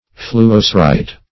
Search Result for " fluocerite" : The Collaborative International Dictionary of English v.0.48: Fluocerine \Flu`o*ce"rine\, Fluocerite \Flu`o*ce"rite\, n. [Fluo- + cerium.]